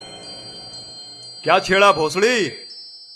kya cheda bsd Meme Sound Effect
Category: Reactions Soundboard